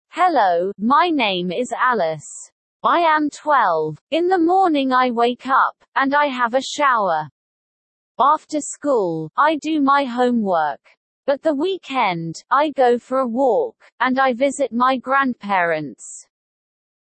Compréhension de l’oral